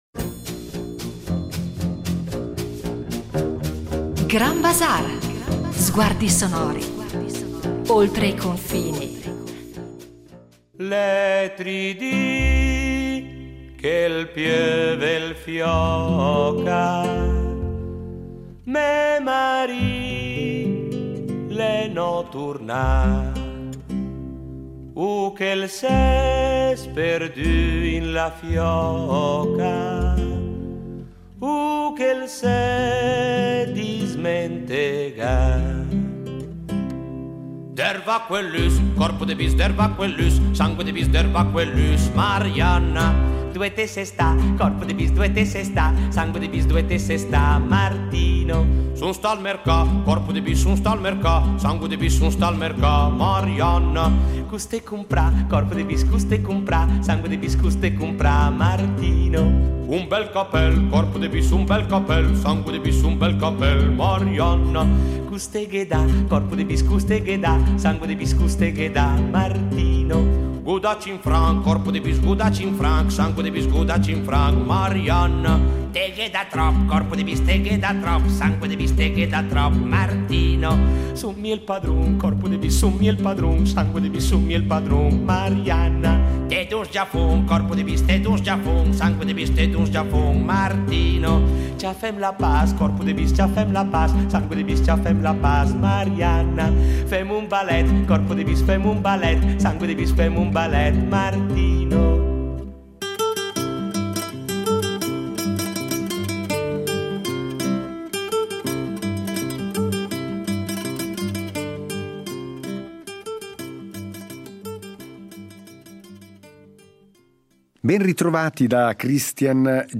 La trasmissione radiofonica “ Grand Bazaar ” propone una serie speciale di puntate dedicate alla figura di Nanni Svampa, tra i più importanti interpreti della canzone milanese e protagonista della riscoperta del repertorio popolare lombardo.